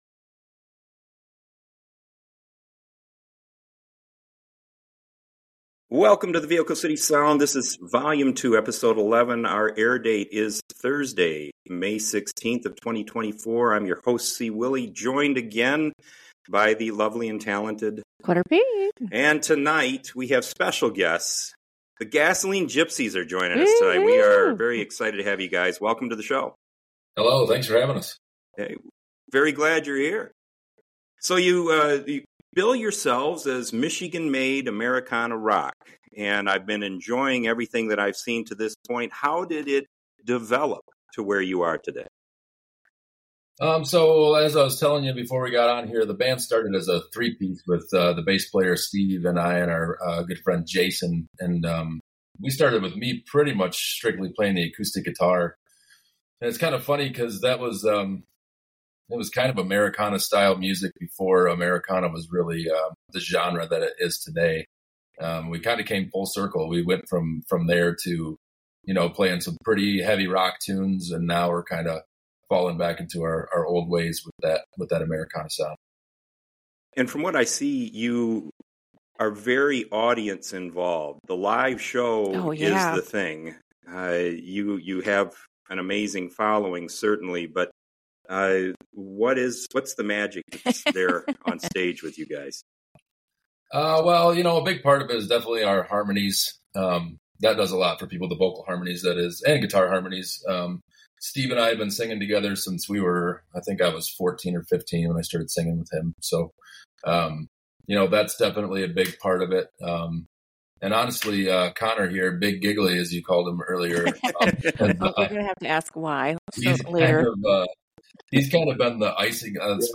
Michigan made Americana Rock, you'll love this mix of music as The Gasoline Gypsies share their talents...and...who's going to be a Daddy!